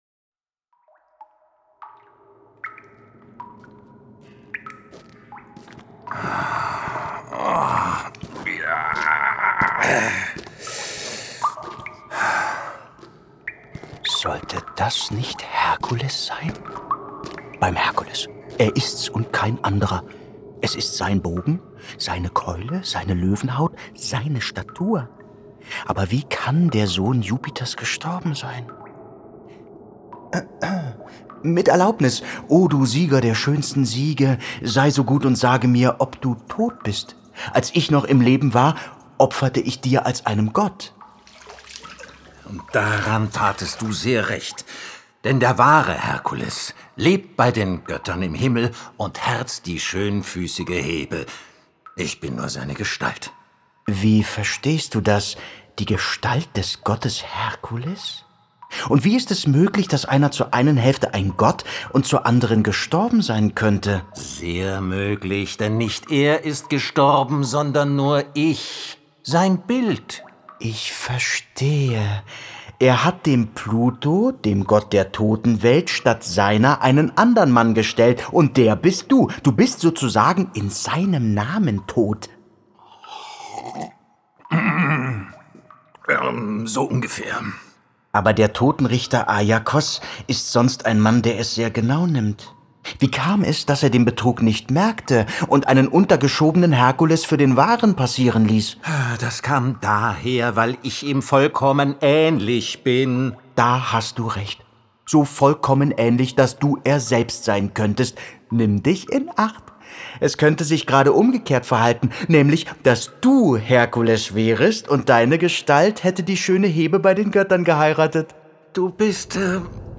Ein Hörspaziergang
Töne aus der Unterwelt
Begehbarer Hörspiel-Parcour